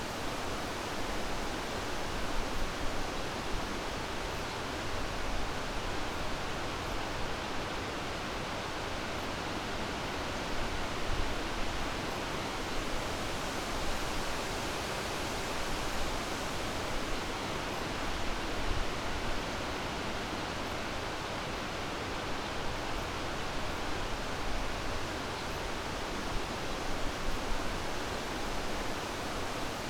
Wind_Light_Forest.ogg